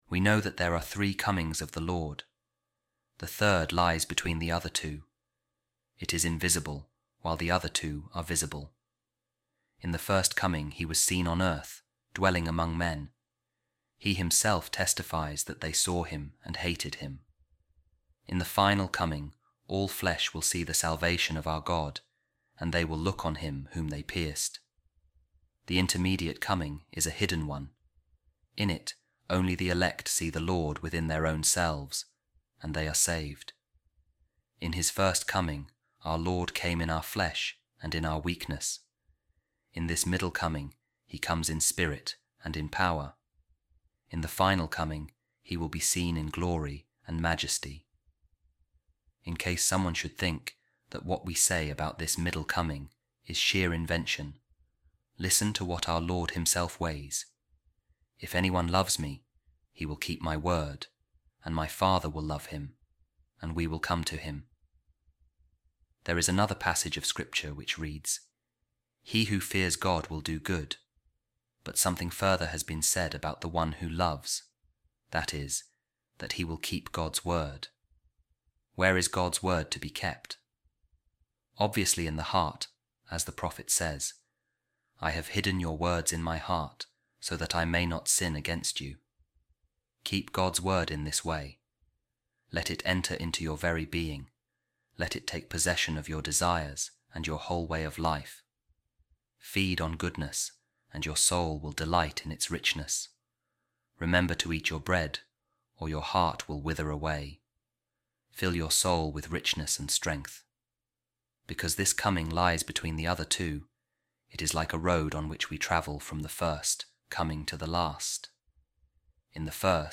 Office Of Readings | Advent Wednesday Week 1 | A Reading From A Sermon By Saint Bernard | The Word Of God Will Come Within Us